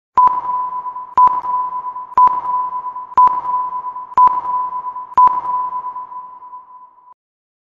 Play Countdown 5 Seconds - SoundBoardGuy